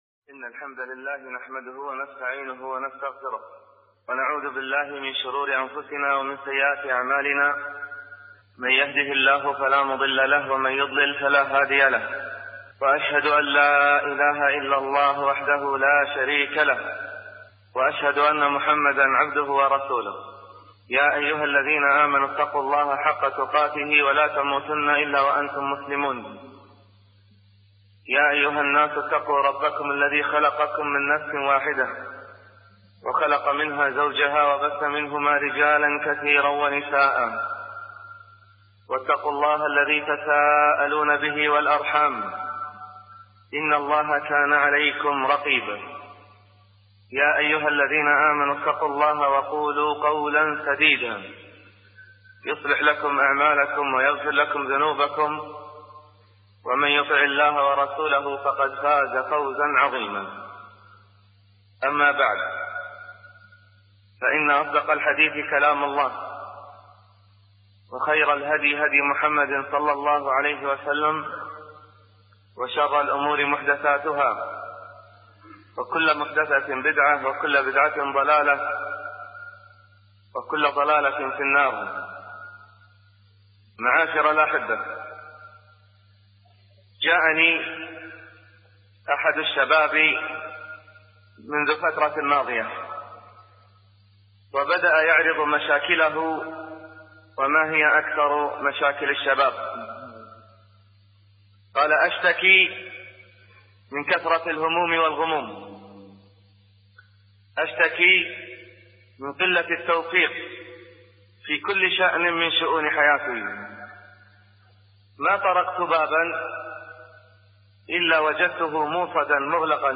المحاضرات الصوتية